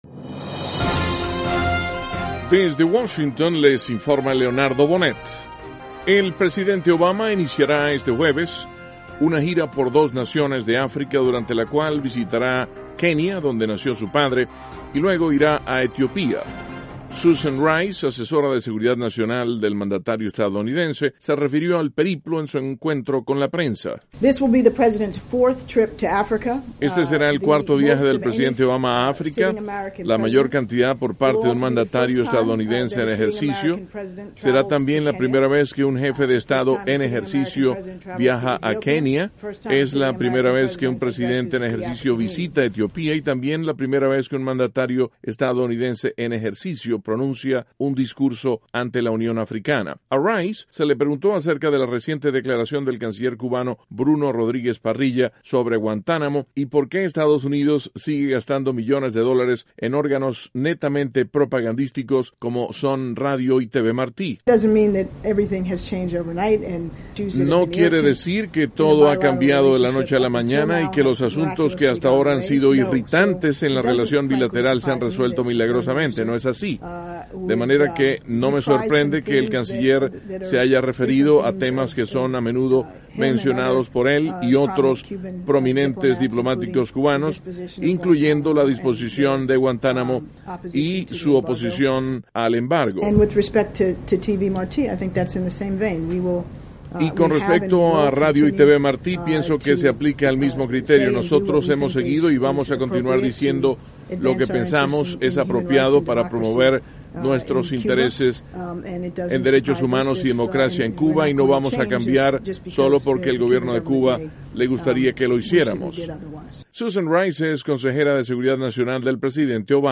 Susan Rice, Consejera de Seguridad Nacional del presidente Obama, habla de la gira del mandatario por África ; El consejero del Departamento de Estado, Thomas Shannon, se refiere a la política del gobierno de Washington hacia Centroamérica, en un coloquio que tuvo lugar en Madrid.